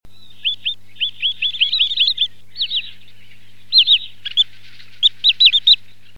Chevalier Sylvain Tringa glareola